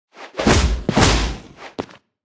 Media:Sfx_Anim_Classic_Wizard.wavMedia:Sfx_Anim_Super_Wizard.wavMedia:Sfx_Anim_Ultra_Wizard.wav 动作音效 anim 在广场点击初级、经典、高手和顶尖形态或者查看其技能时触发动作的音效
Sfx_Anim_Classic_Wizard.wav